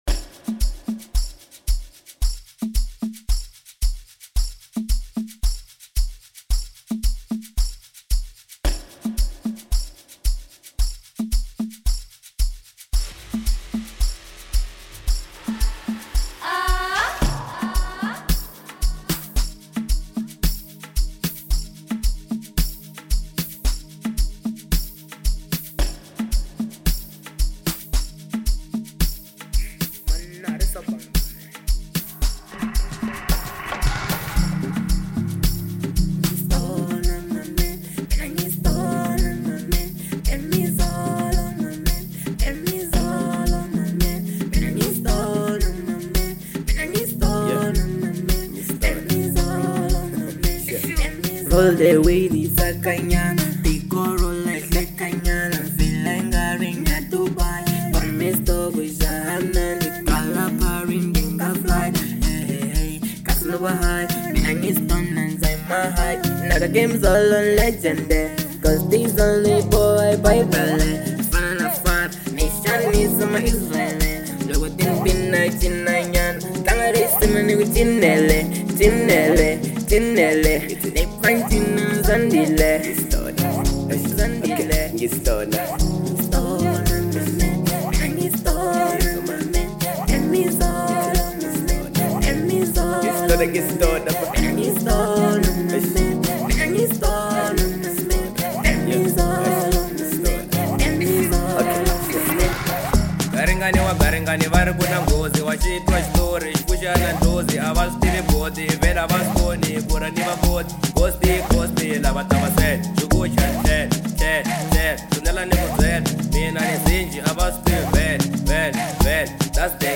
06:07 Genre : Amapiano Size